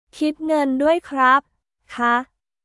キット グン ドゥアイ クラップ／カー